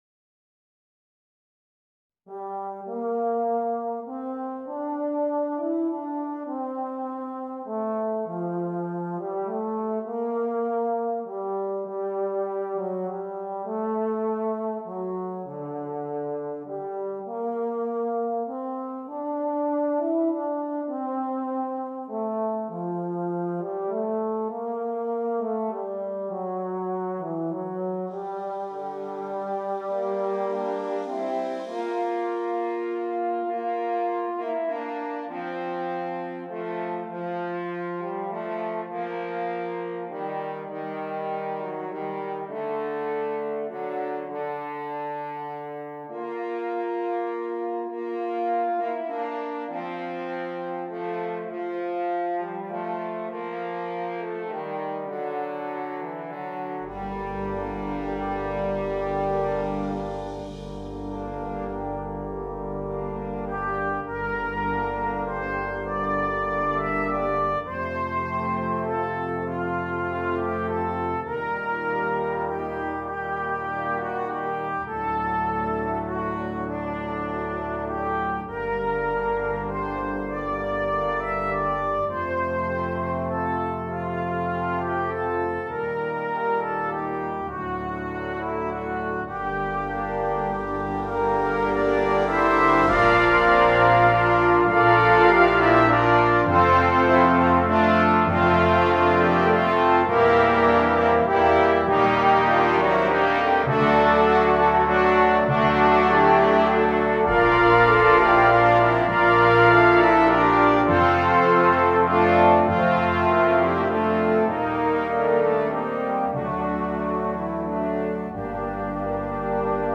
Brass Choir
Traditional